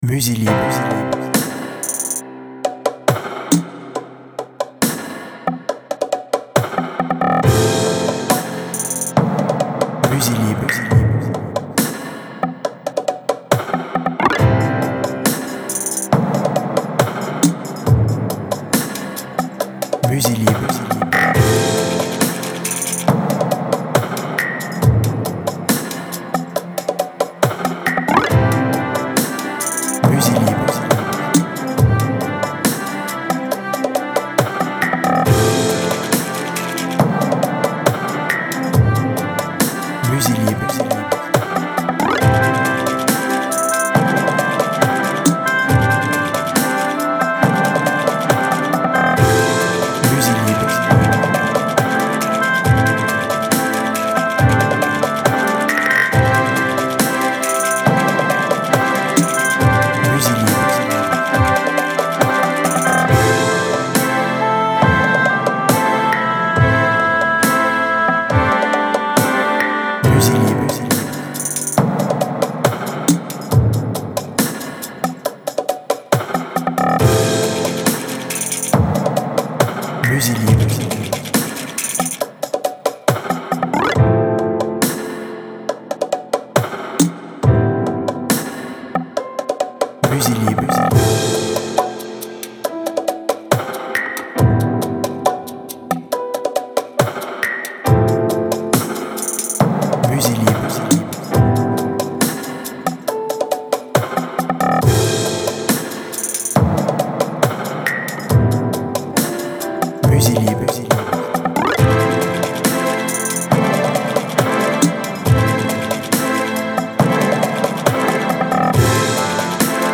Voici une musique orchestrale cinématographique inspirante!
BPM Lent